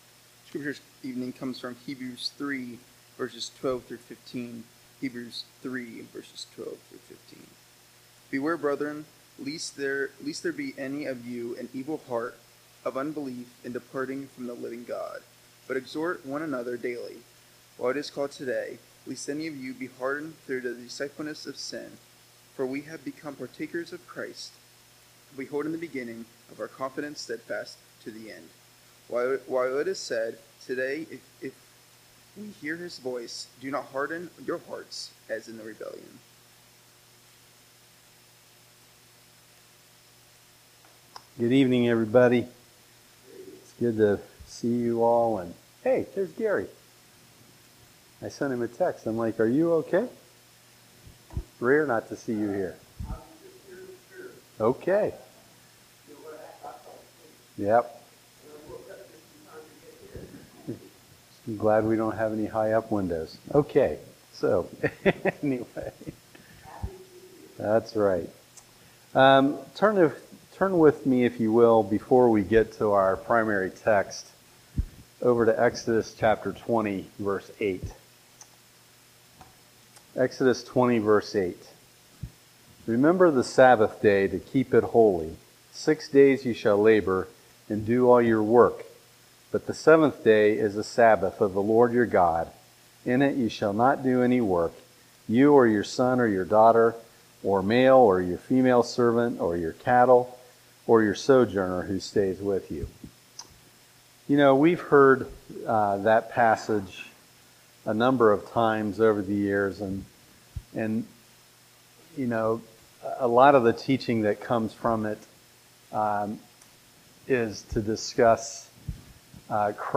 Passage: Hebrews 3:12-15 Service: Sunday Evening Topics